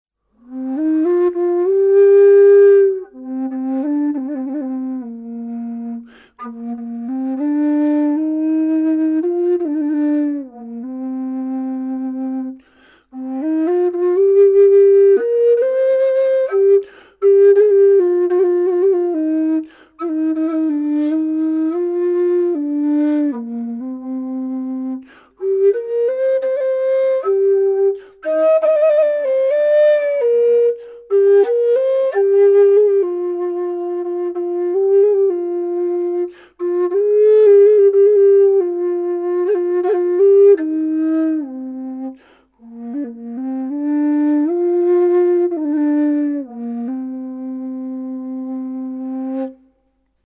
Bass Bb Whistles
made out of light-weight 28mm bore aluminium tubing with elbow bend head.
Bass_Bb_sample-7.mp3